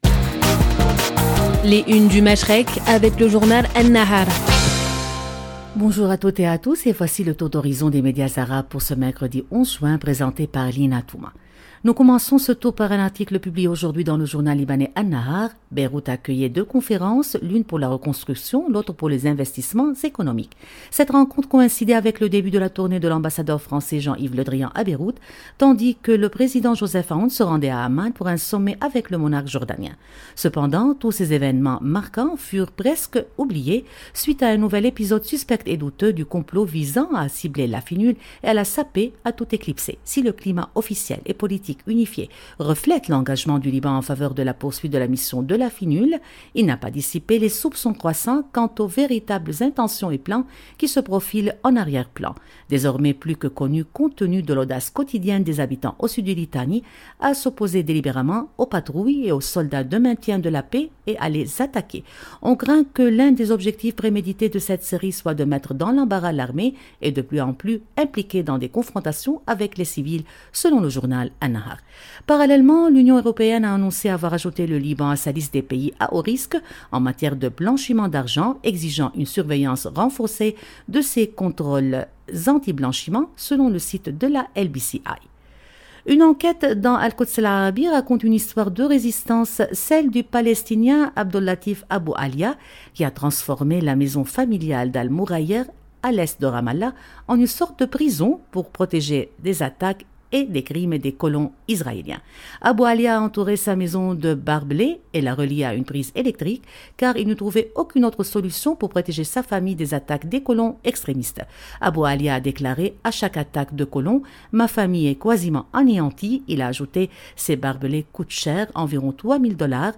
Depuis Paris, Radio Orient vous propose chaque matin un tour d’horizon des grands titres de la presse du Machrek, en partenariat avec An-Nahar : analyses, regards croisés et clés de lecture régionales. Radio Orient vous présente, en partenariat avec le journal libanais An-Nahar , une revue de presse complète des grands titres du Moyen-Orient et du Golfe. À travers des regards croisés et des analyses approfondies, cette chronique quotidienne offre un décryptage rigoureux de l’actualité politique, sociale et économique de la région, en donnant la parole aux médias arabes pour mieux comprendre les enjeux qui façonnent le Machrek. 0:00 4 min 57 sec